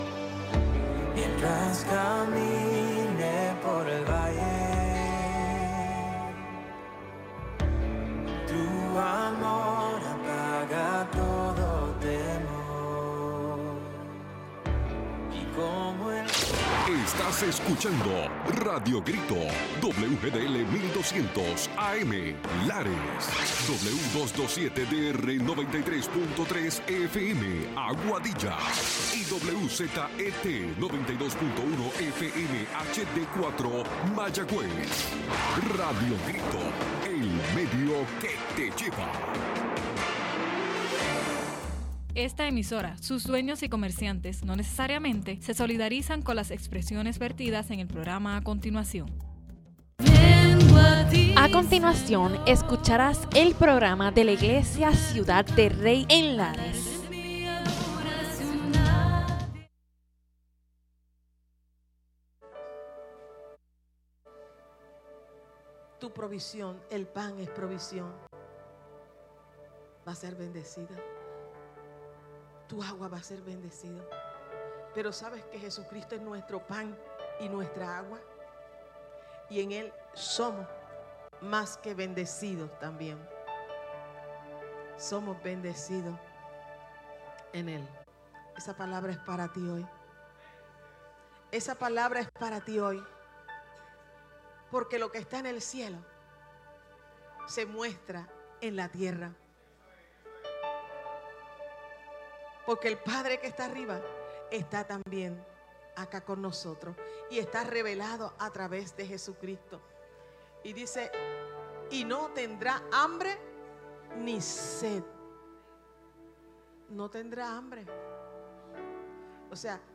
Los hermanos de Ciudad del Rey nos traen un programa especial desde su servicio en la iglesia.